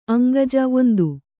♪ aŋgaja